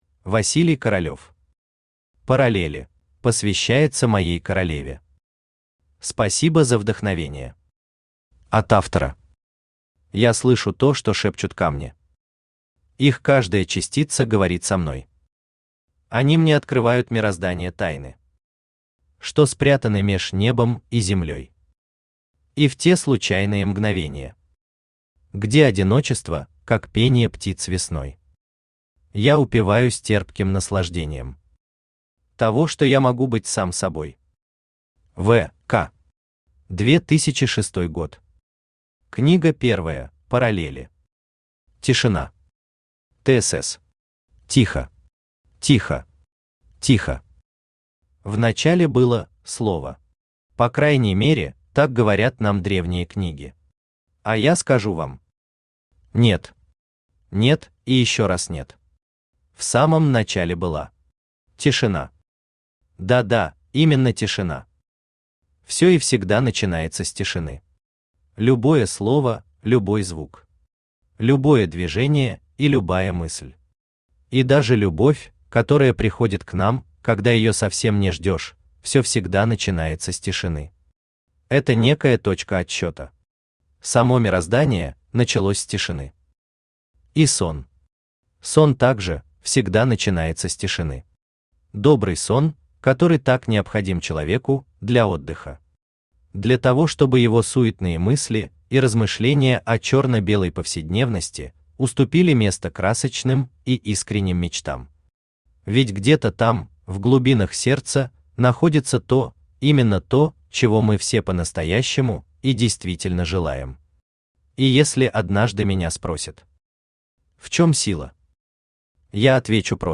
Аудиокнига Параллели | Библиотека аудиокниг
Aудиокнига Параллели Автор Василий Королев Читает аудиокнигу Авточтец ЛитРес.